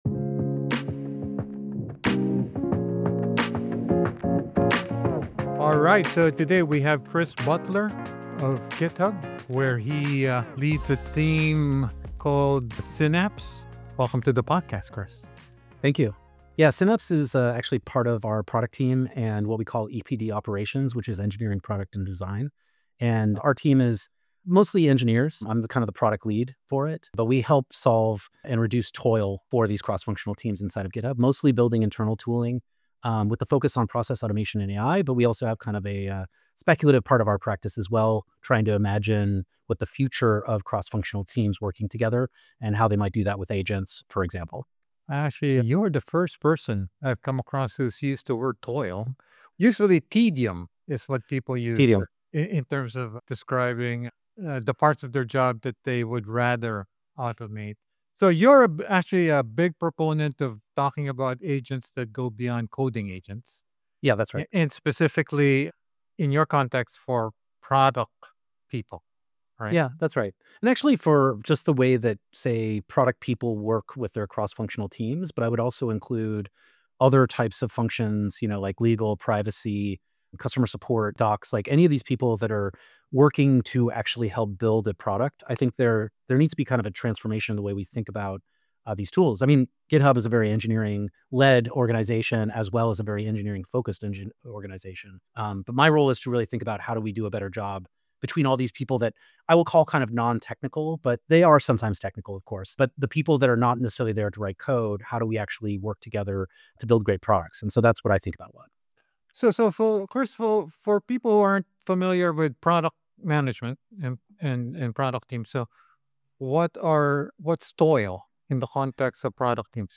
About the Generative AI in the Real World podcast: In 2023, ChatGPT put AI on everyone’s agenda.